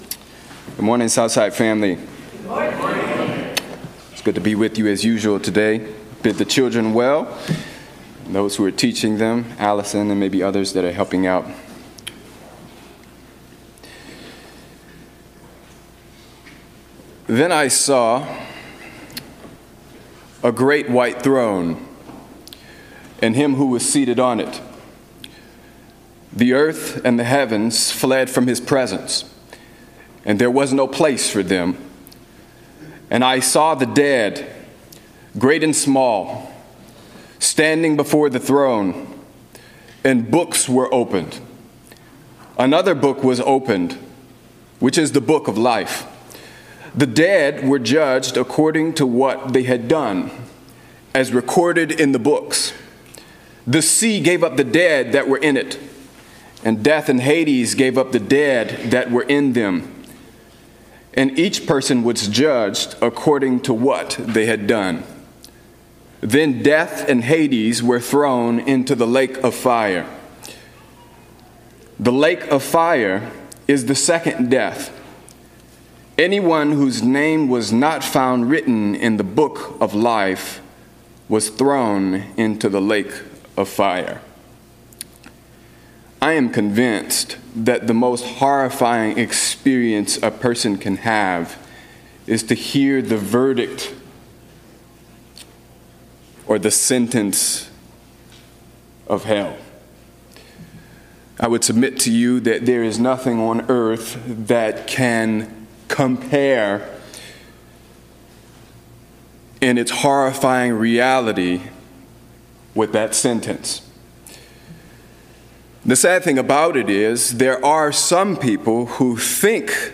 Here’s the sermon text (New International Version):